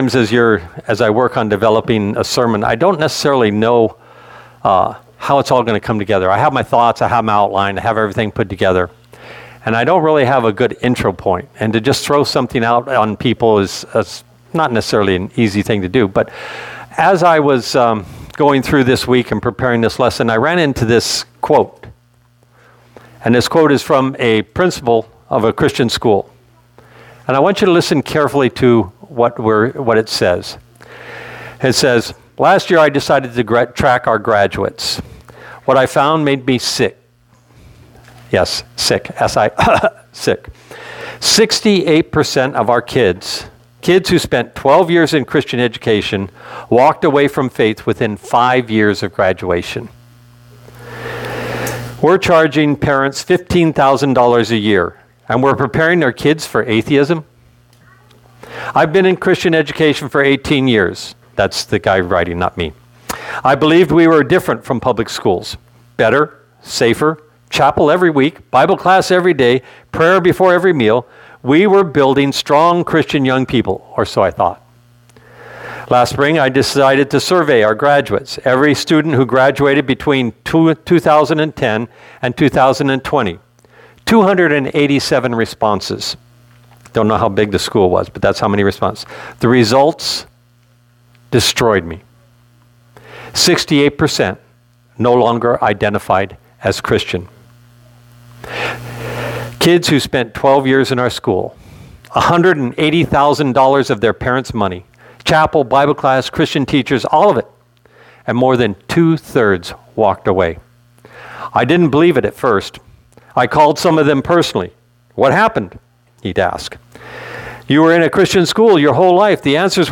All Sermons God’s Charge to Joshua